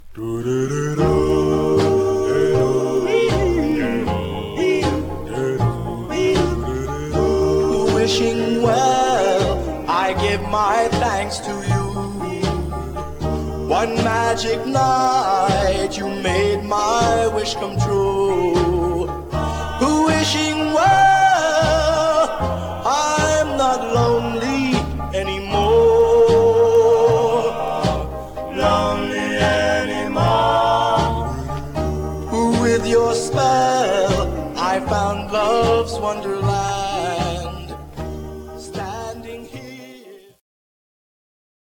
Mono
Male Black Groups